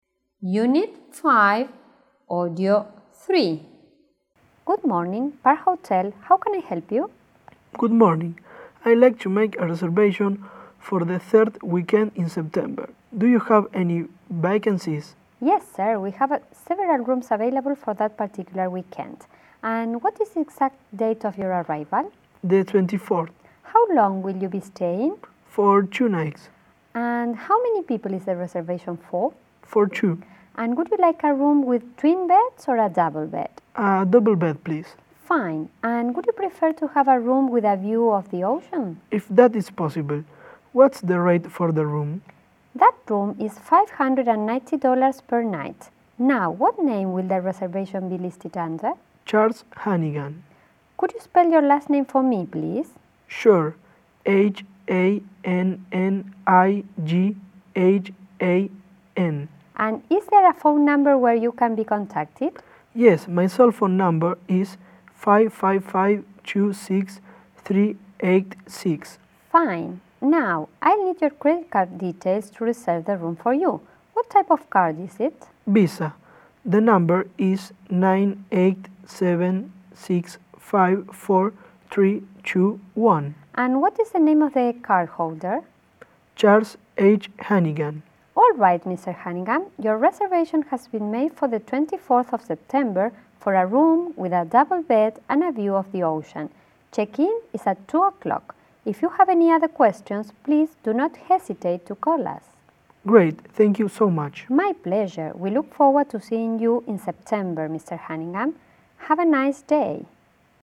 Conversations: